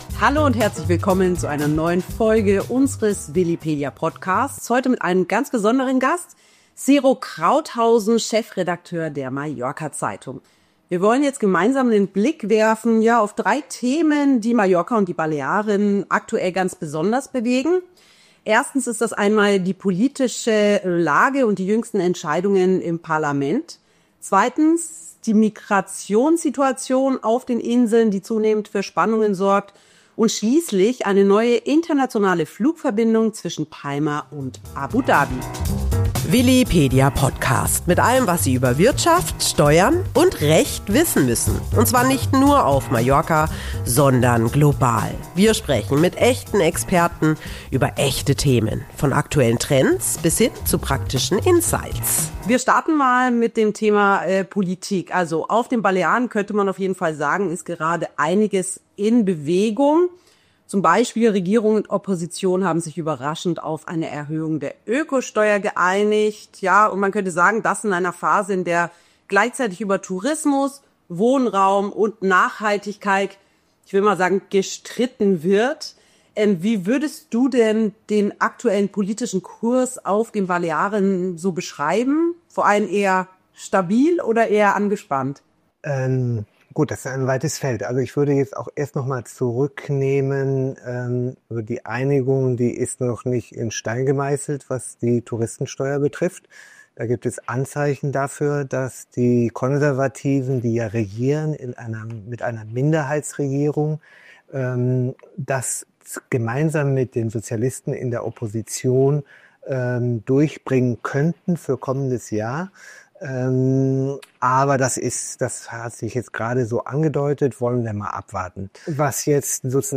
Ein informativer und aktueller Talk mit journalistischer Tiefe – über die politische Lage, gesellschaftliche Entwicklungen und internationale Perspektiven auf den Balearen.